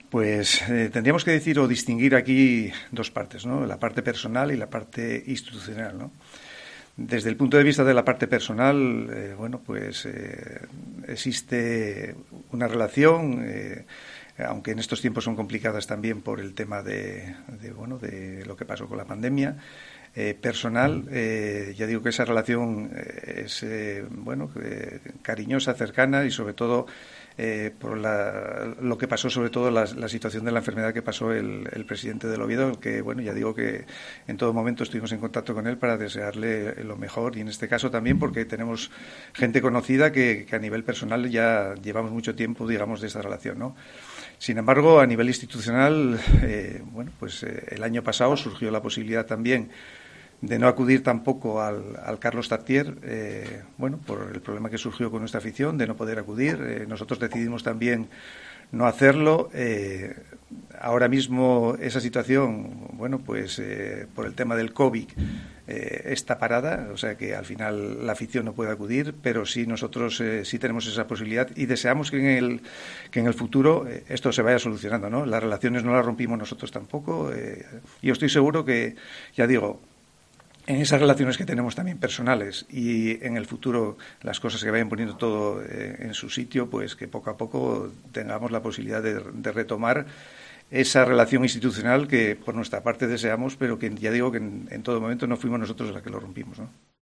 Joaquín Alonso responde sobre las relaciones entre el Sporting y el Oviedo